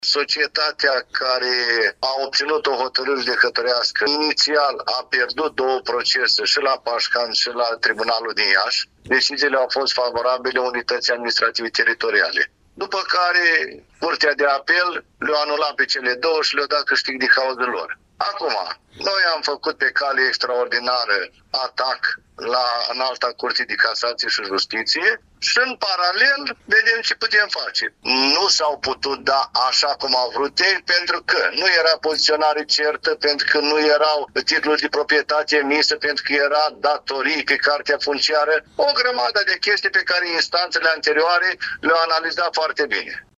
Primarul comunei Bălțați, Vasile Aștefanei, a declarat că a înaintat o cale extraordinară de atac la Înalta Curte de Casație și Justiție, prin care solicită revizuirea și contestarea în anulare la o altă Curte de Apel din țară.